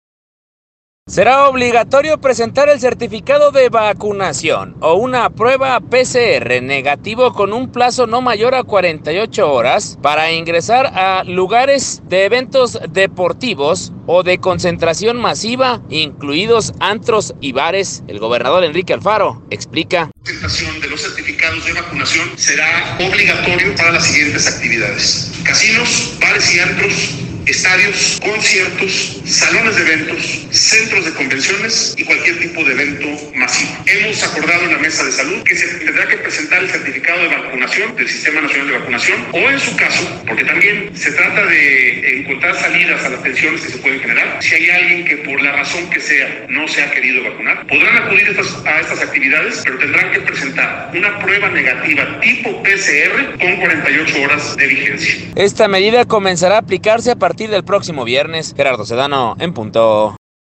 El gobernador, Enrique Alfaro, explica: